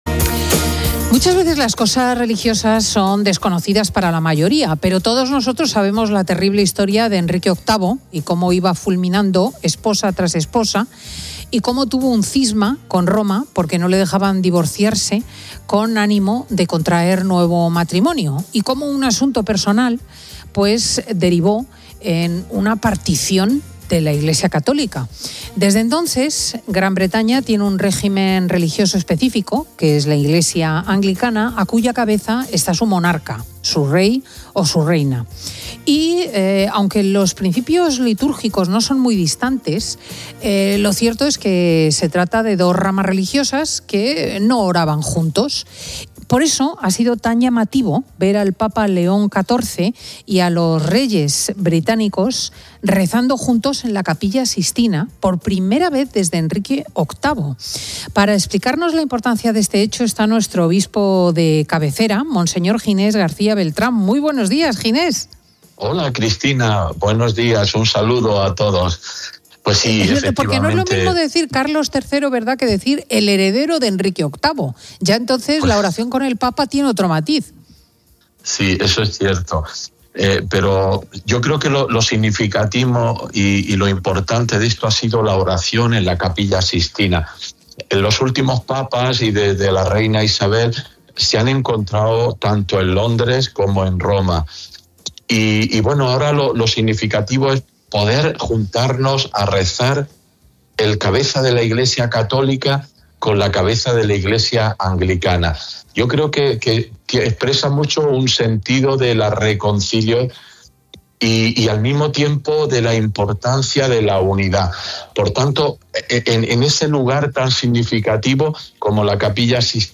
Para analizar la importancia de este hecho, el obispo de Getafe, monseñor Ginés García Beltrán, ha intervenido en el programa ‘Fin de Semana COPE’, presentado por Cristina López Schlichting.